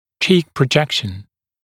[ʧiːk prə’ʤekʃn][чи:к прэ’джэкшн]выступание щек